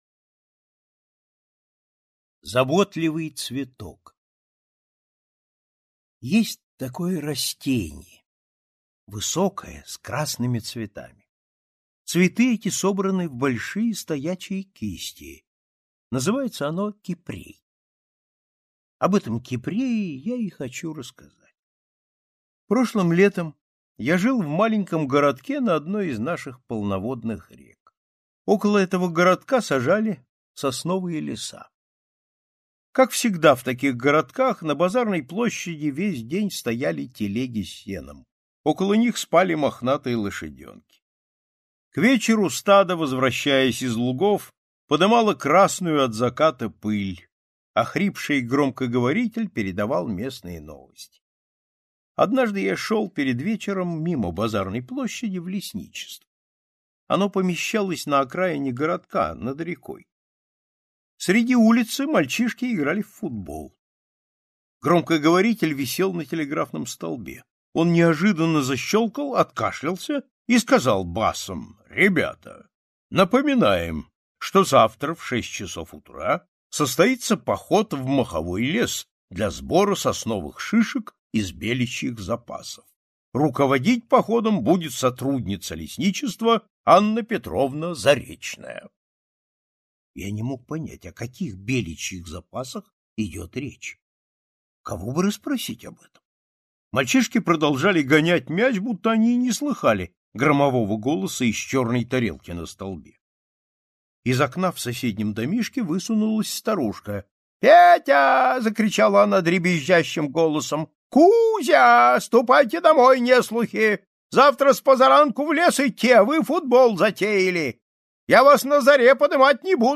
Аудиорассказ «Заботливый цветок»
Хорошая озвучка, приятная и класно то что можно скорость менять, удобно.